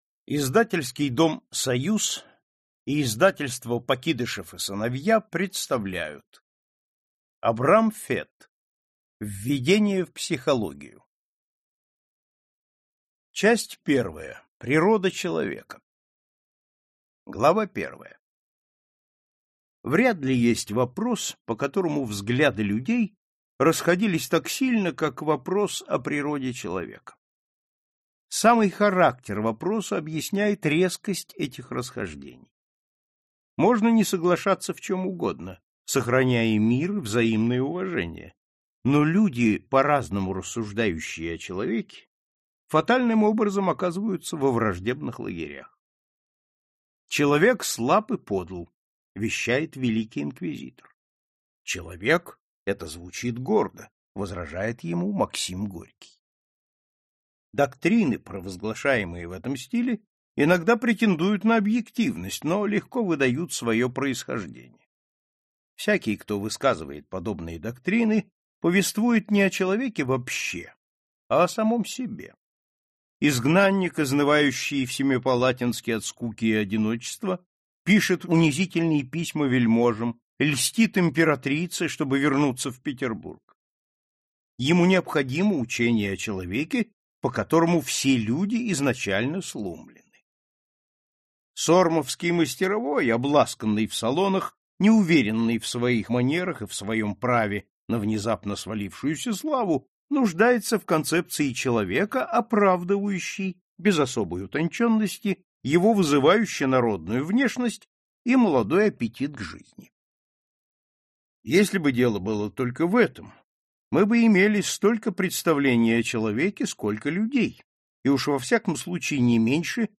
Аудиокнига Введение в психологию | Библиотека аудиокниг